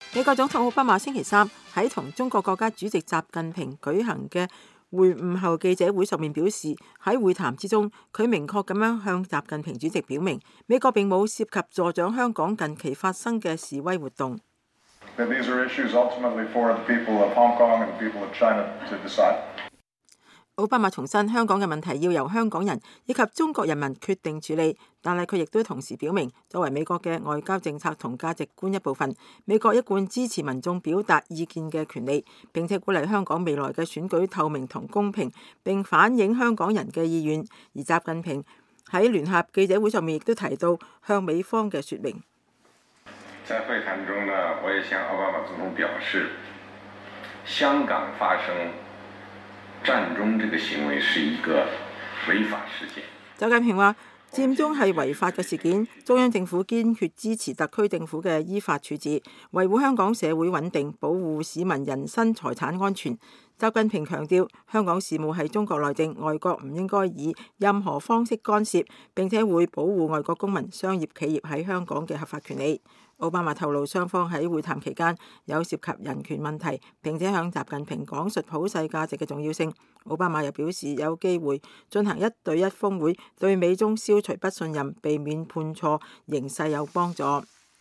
美國總統奧巴馬星期三在同中國國家主席習近平舉行的會晤後記者會上表示，在會談中，他明確地向習主席表明，美國沒有涉及助長香港近期發生的示威活動。 奧巴馬重申，香港的問題要由香港人及中國人民決定處理，但是他也同時表明，作為美國的外交政策和價值觀一部分，美國一貫支持民眾表達意見的權利，並鼓勵香港未來的選舉透明和公平，並反映香港人的意願。